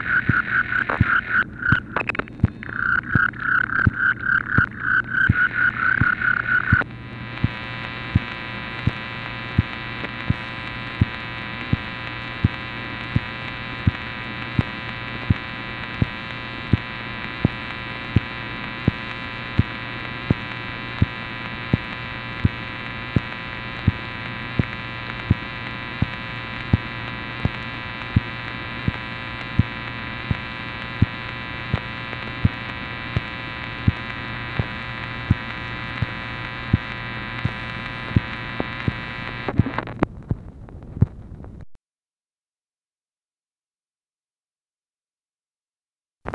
MACHINE NOISE, November 9, 1965
Secret White House Tapes